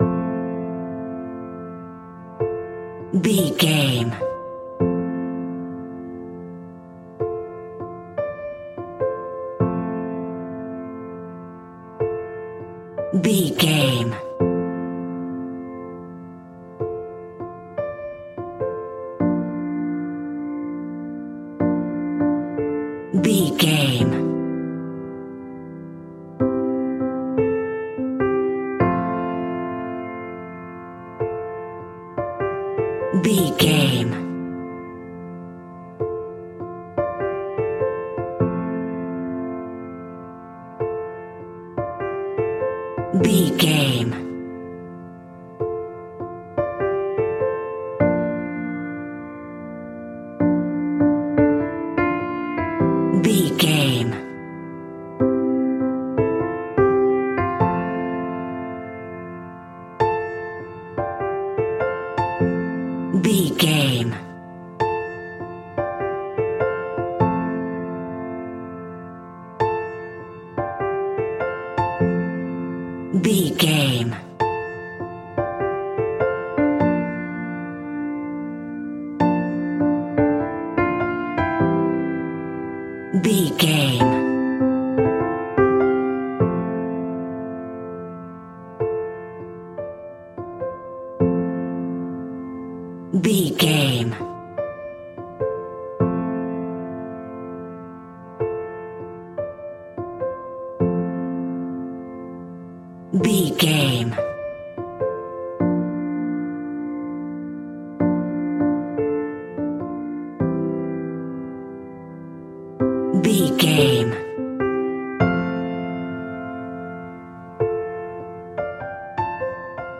Ionian/Major
Slow
tranquil
synthesiser
drum machine